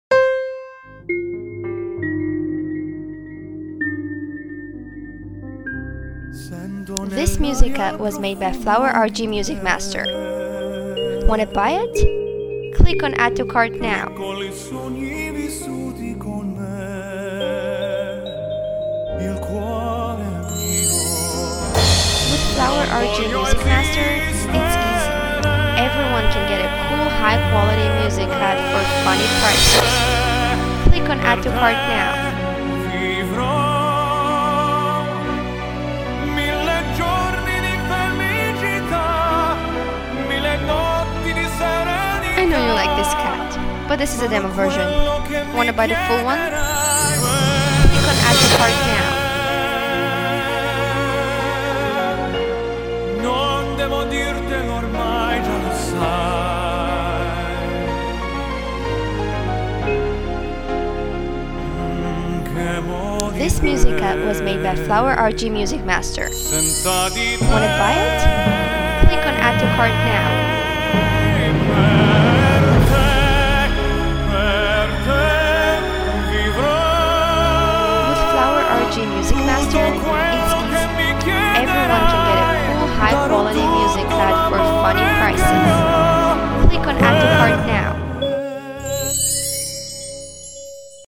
Italian classic
You have to take this emotional cut <3